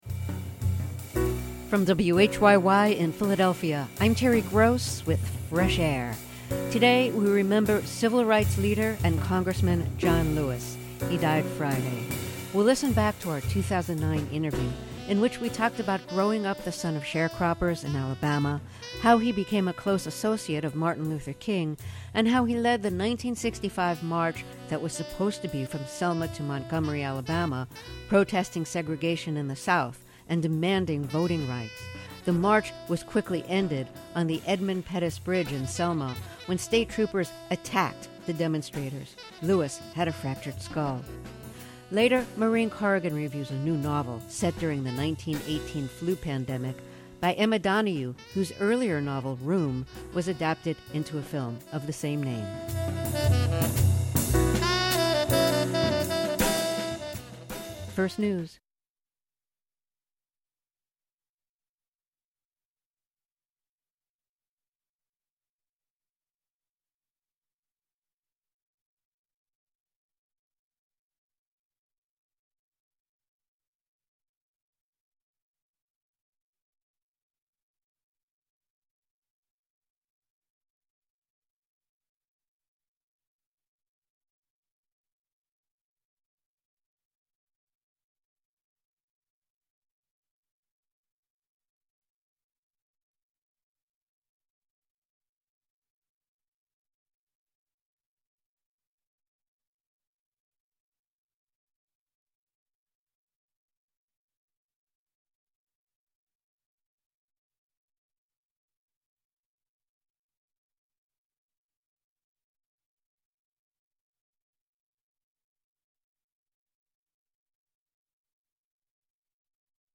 King, Martin Luther, Jr., 1929-1968 | Fresh Air Archive: Interviews with Terry Gross